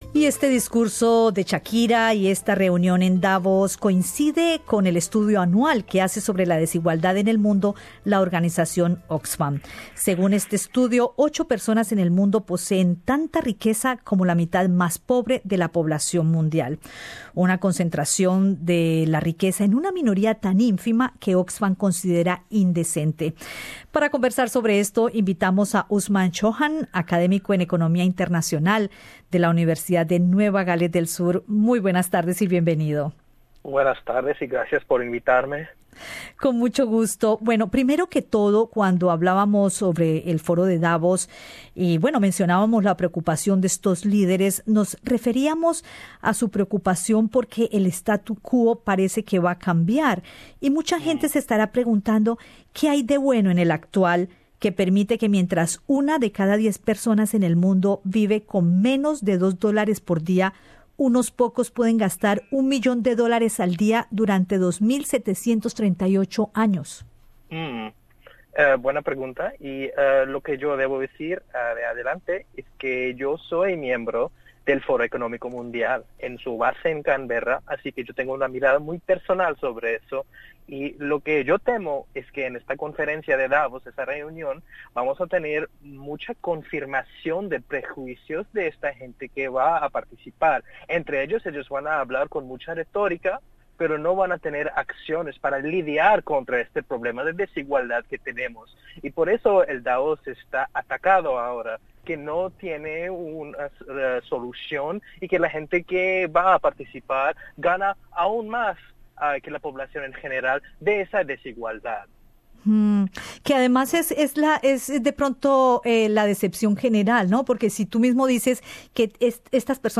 Escucha la conversación en nuestro podcast .